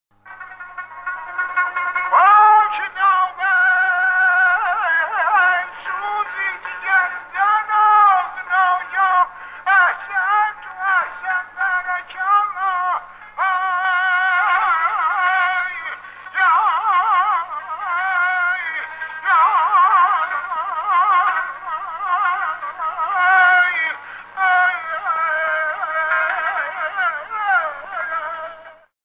Hüseynqulu Sarabskinin səsyazısı: Məcnunun 28 saniyəlik oxuması